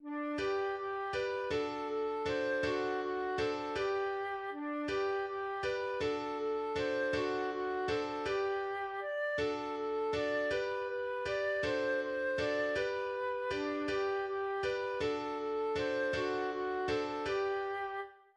Lied eines Perainegottesdienstes
} { g2 s4 } } } \relative c' { \time 3/4 \partial 4 \tempo 4=160 \key g \major \set Staff.midiInstrument="Flute" d4